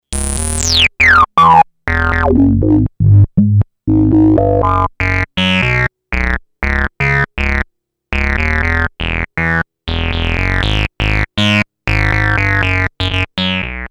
edit VOICE analog monophonic module based on subtractive synthesis.
demo bass